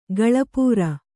♪ gaḷapūra